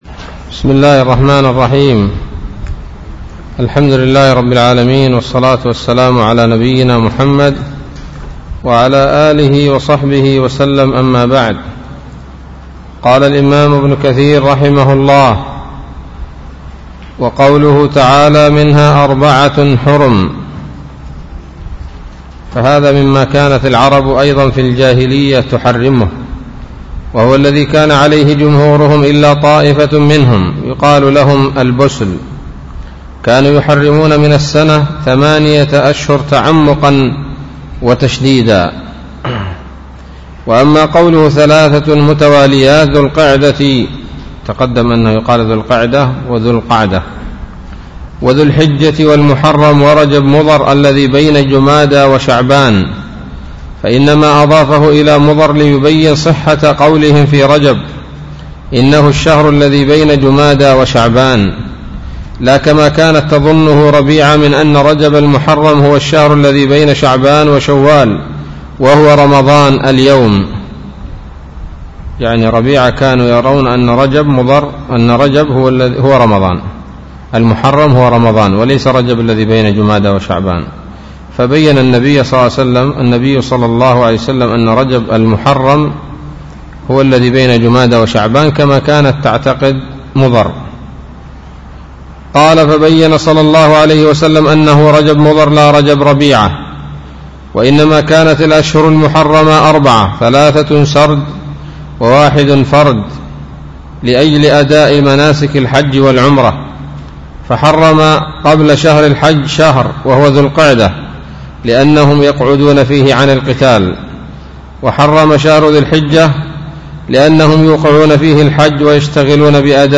الدرس السابع عشر من سورة التوبة من تفسير ابن كثير رحمه الله تعالى